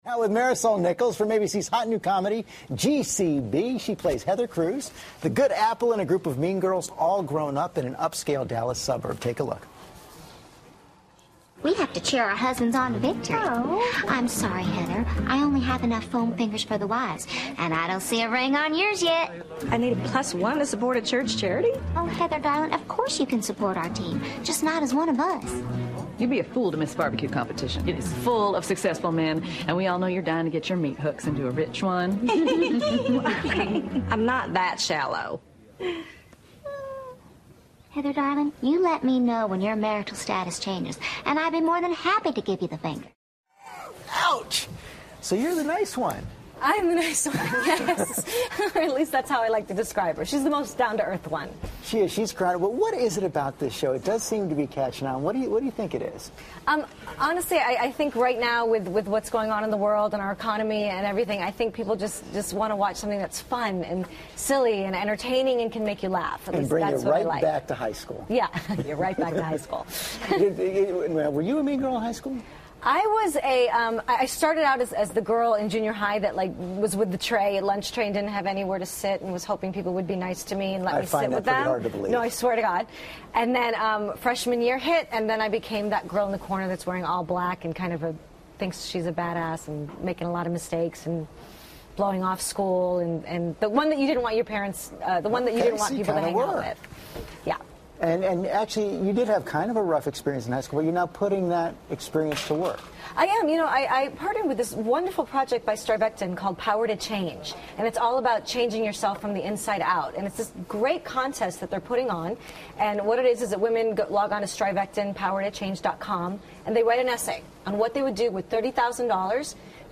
访谈录 2012-05-03&05-05 ABC新剧《虔诚美人》主角专访 听力文件下载—在线英语听力室